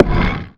creaking_heart_hit5.ogg